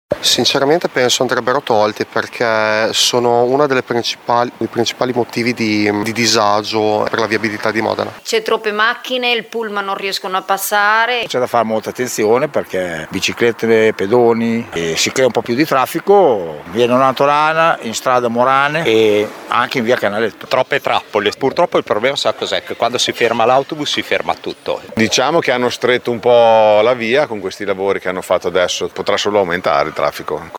Le interviste ai modenesi: